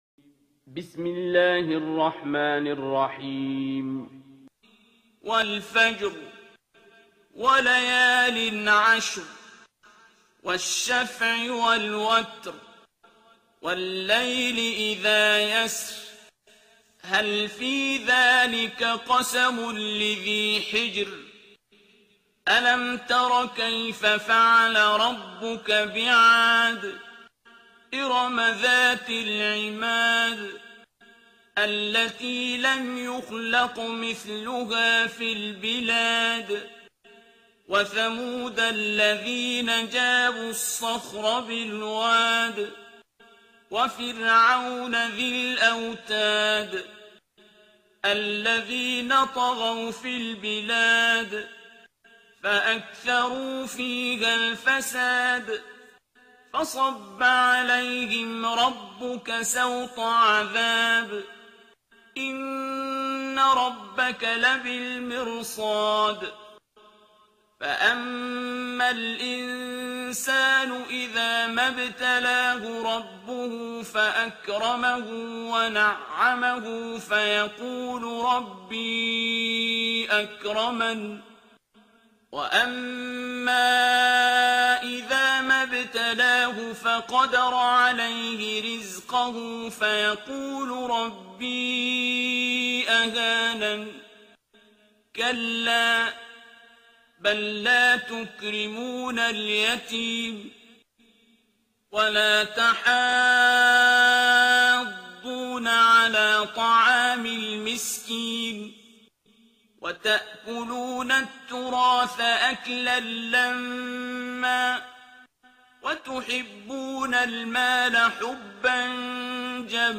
ترتیل سوره فجر با صدای عبدالباسط عبدالصمد